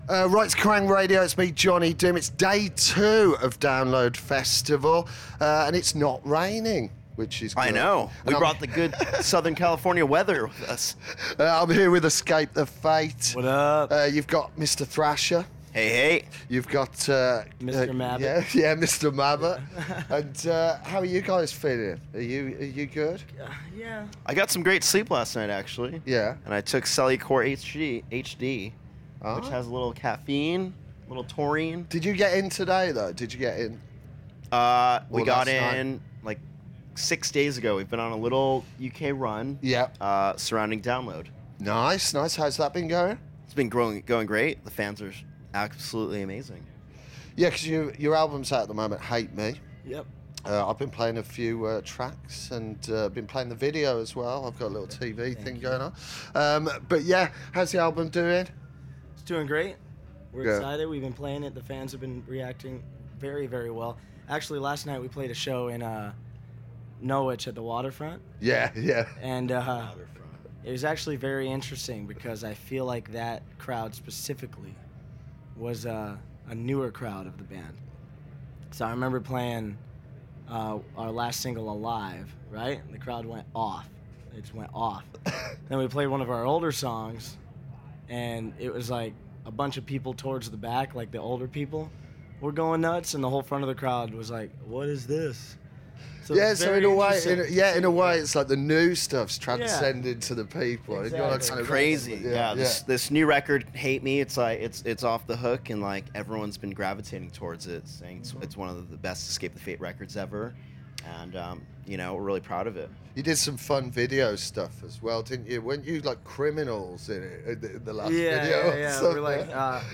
Escape The Fate backstage at Download 2016